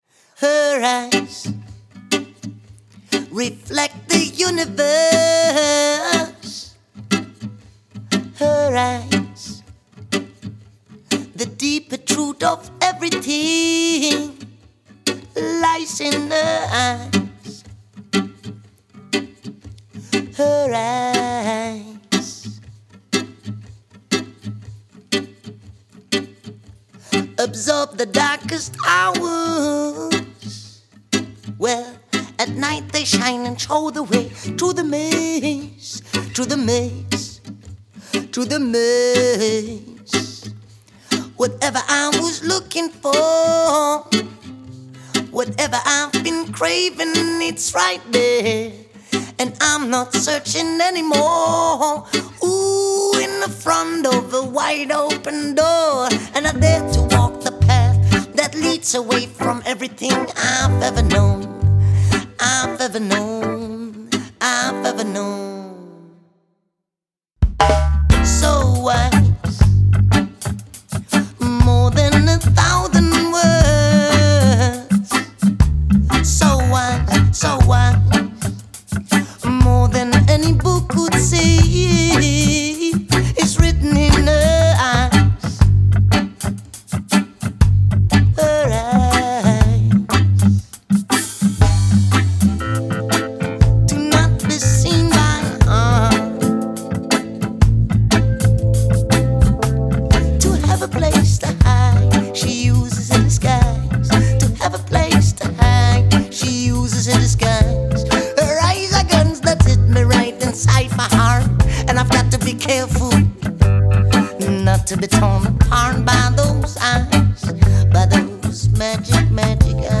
romântica
Vocais
Teclados
Baixo
Bateria